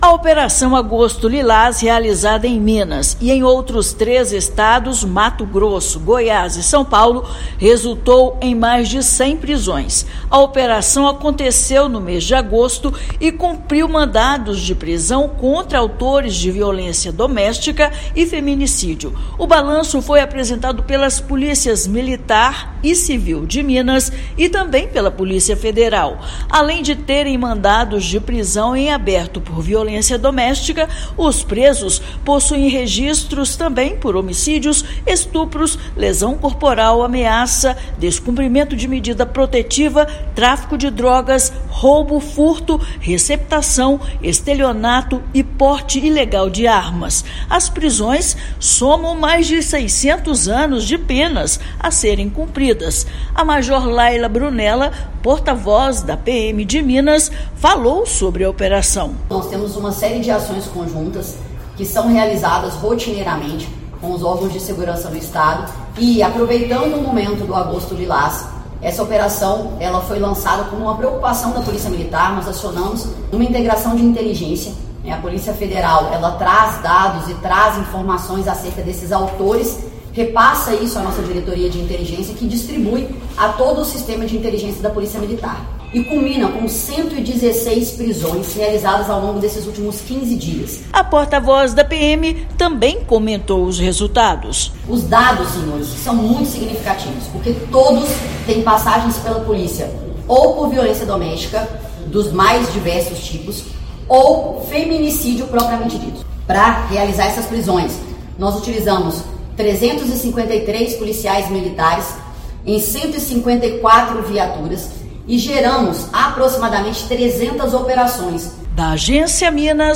Balanço das ações do Agosto Lilás, mês de combate a esse tipo de crime, foi apresentado por representantes das Forças de Segurança. Ouça matéria de rádio.